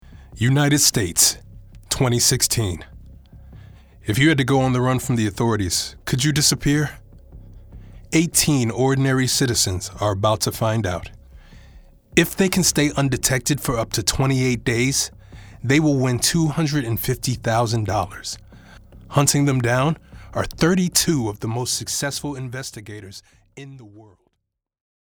Narration VO
Voice Over